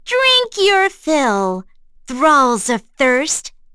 Erze-Vox_Skill5.wav